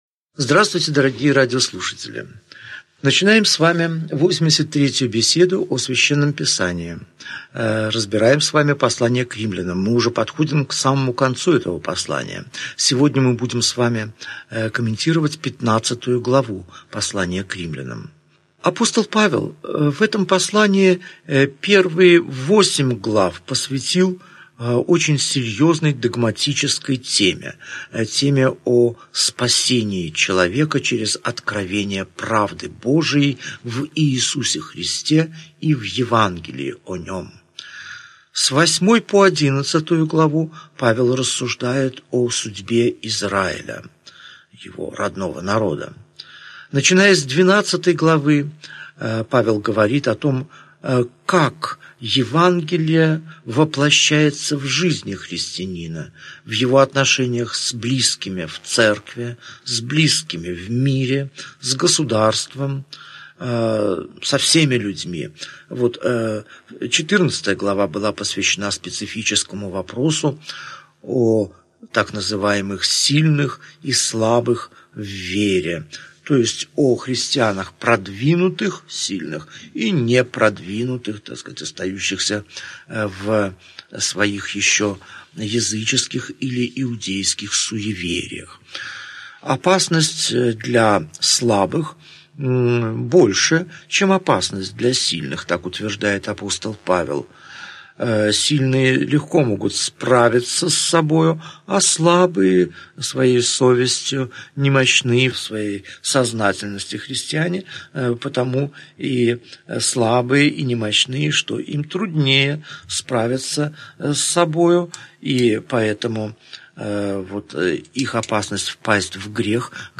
Аудиокнига Беседа 83. Послание к Римлянам. Глава 15 – глава 16 | Библиотека аудиокниг